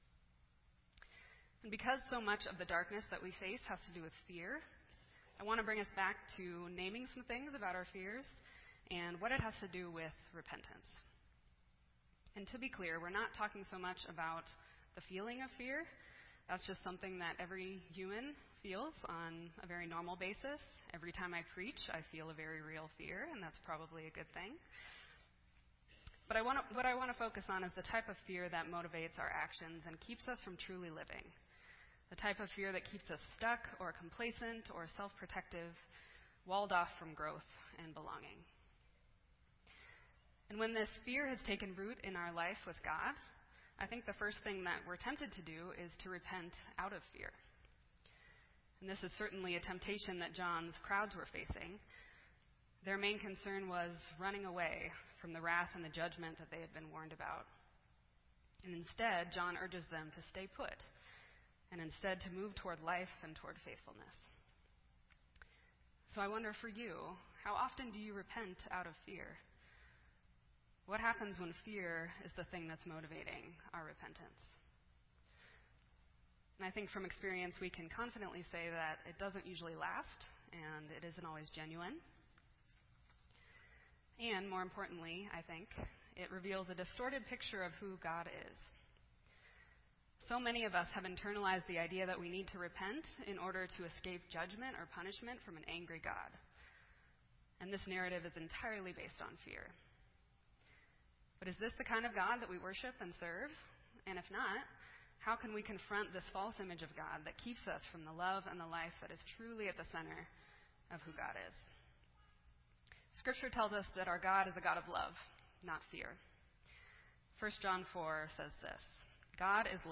This entry was posted in Sermon Audio on January 14